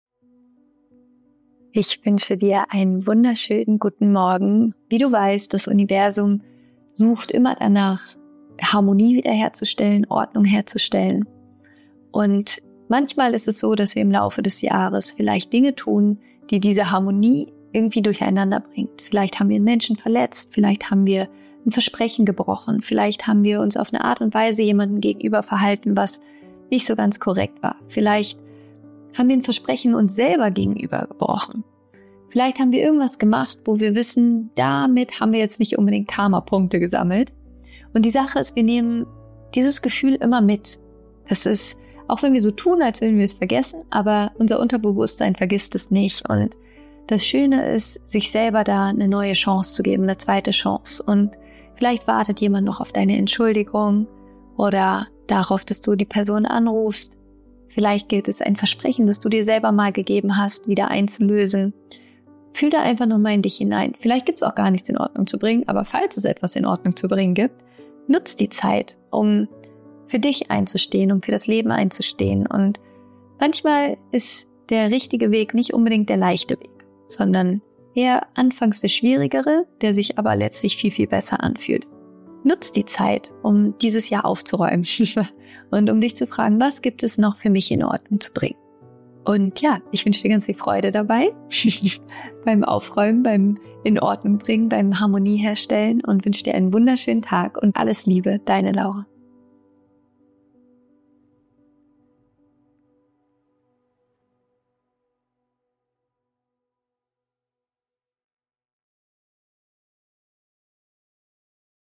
Diese Voice Message ist ein sanfter Impuls, dir selbst eine neue Chance zu schenken und mit Klarheit und Liebe auf das zu schauen, was sich gerade zeigen möchte.